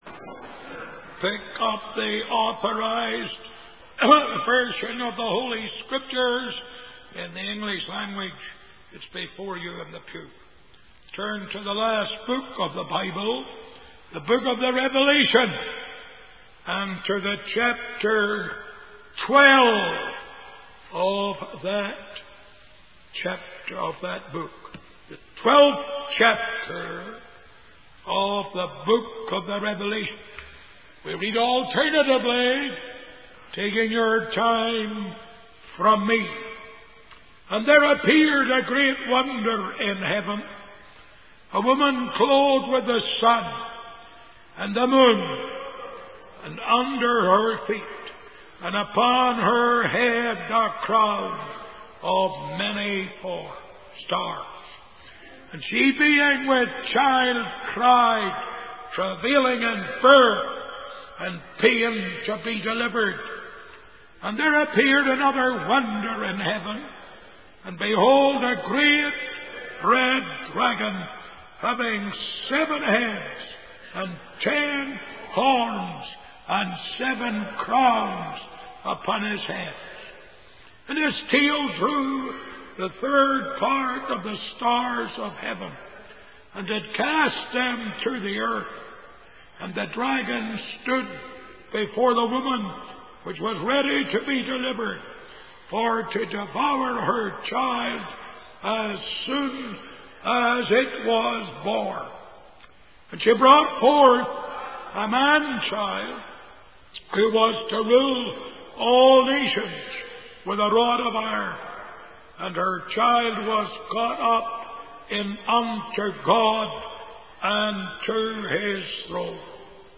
In this sermon, the preacher describes a vision from the book of Revelation in the Bible.